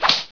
1 channel
meleemiss1.wav